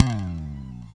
Index of /90_sSampleCDs/Roland L-CD701/BS _Jazz Bass/BS _E.Bass FX